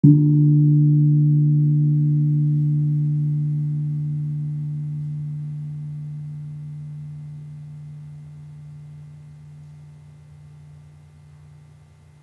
Gamelan / Gong
Gong-D2-p.wav